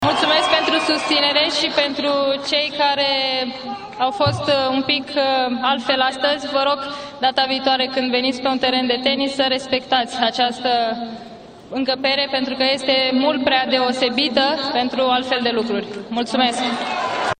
La finalul discursului său în engleză, Simona Halep a continuat: